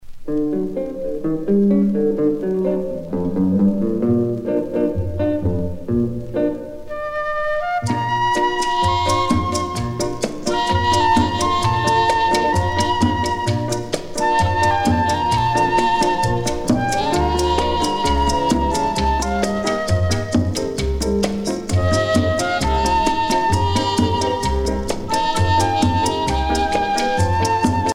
danse : boléro